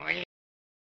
Unused voice clip from Wrecking Crew '98